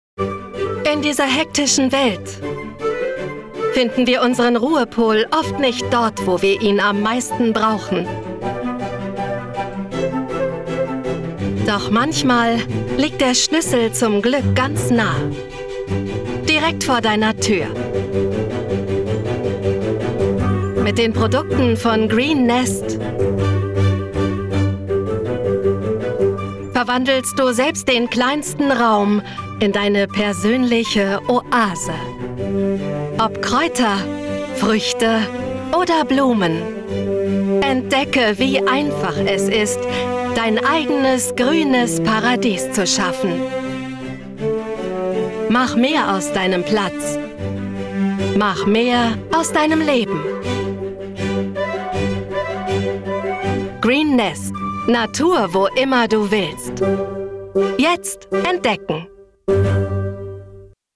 sehr variabel
Commercial (Werbung)